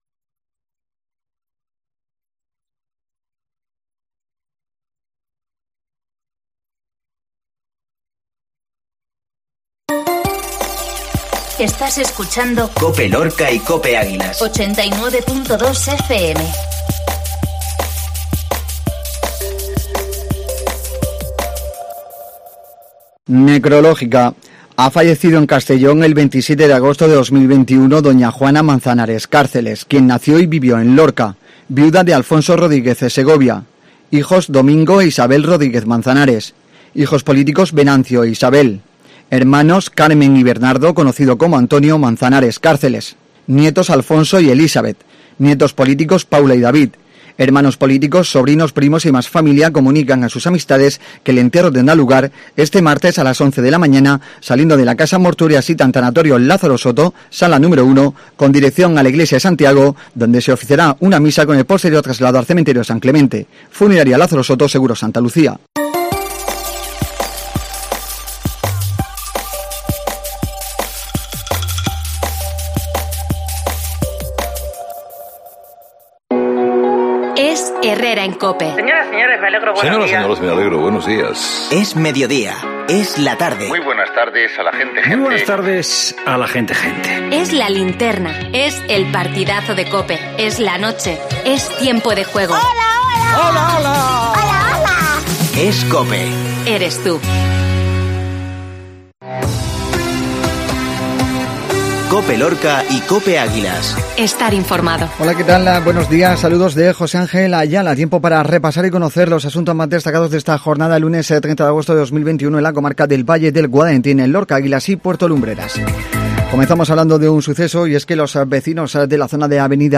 INFORMATIVO MEDIODÍA LUNES